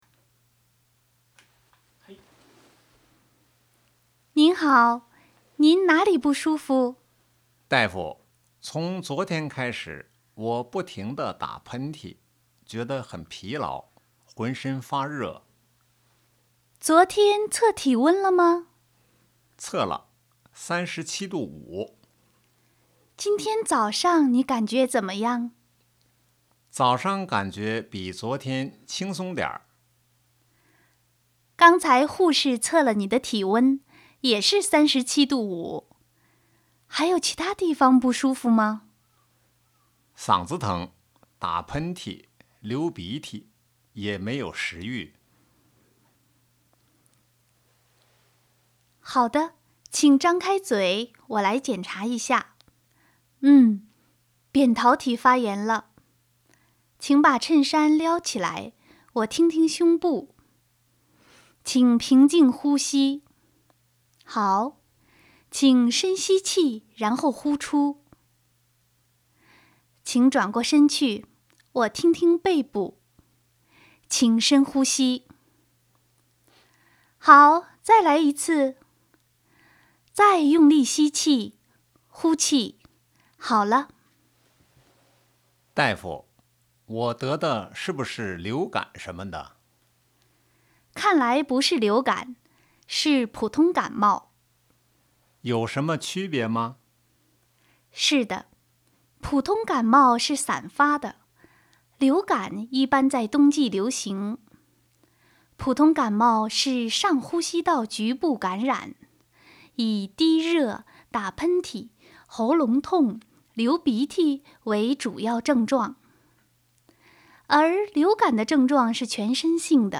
今回は実際の診察室での会話です。少し単語が多くなりましたが、誰もがかかる風邪の診察です。